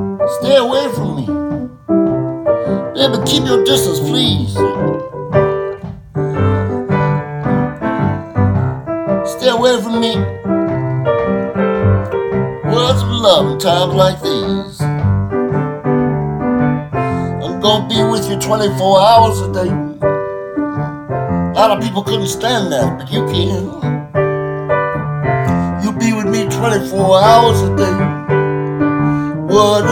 Genre: Singer/Songwriter